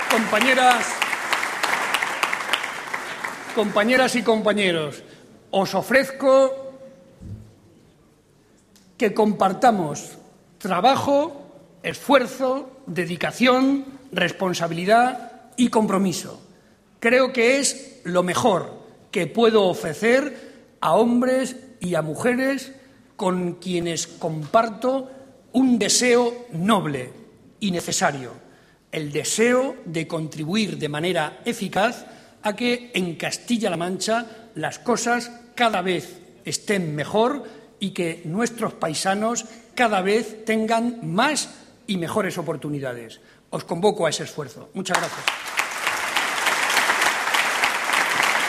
Barreda hizo estas declaraciones durante su intervención en el Comité regional del PSCM-PSOE, en Toledo.
Corte sonoro Barreda Comité Regional